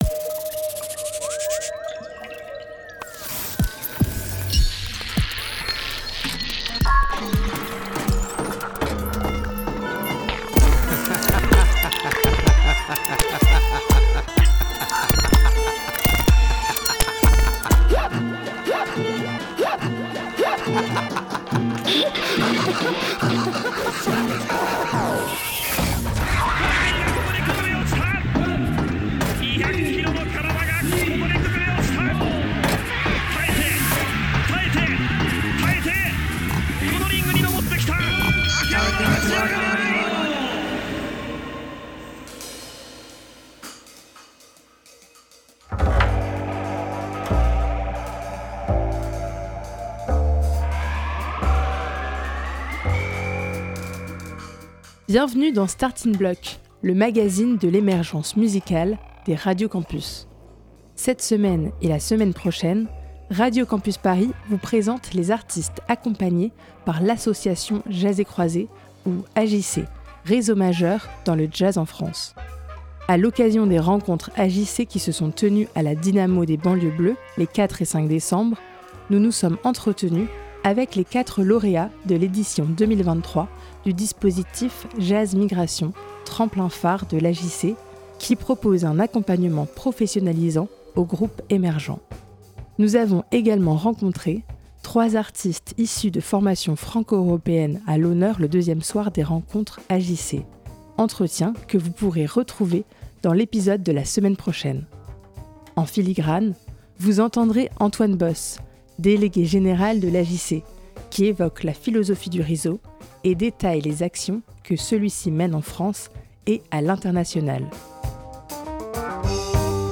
A l'occasion des 30e rencontres AJC qui se sont tenues à la Dynamo de Banlieues Bleues les 4 et 5 décembre, nous nous sommes entretenus avec les quatre lauréat·es de l'édition 2023 du dispositif Jazz Migration, tremplin-phare de l'AJC qui propose un accompagnement professionnalisant aux groupes émergents.